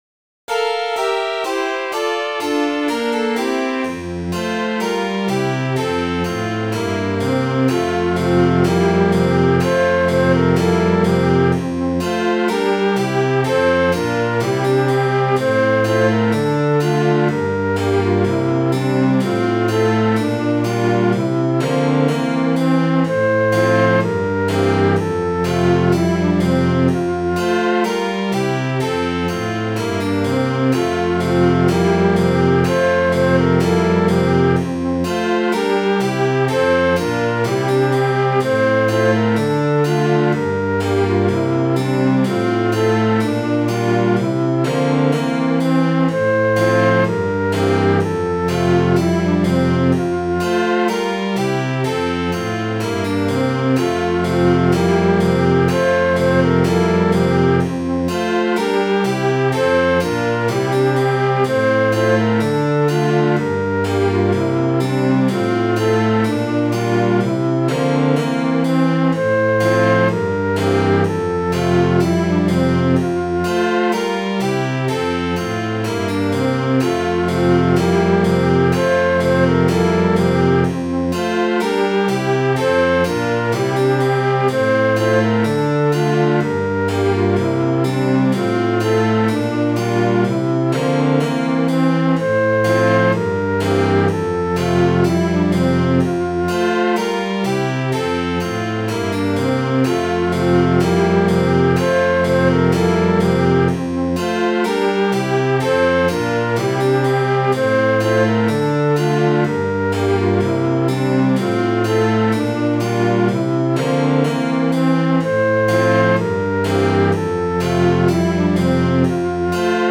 Midi File, Lyrics and Information to O No, John!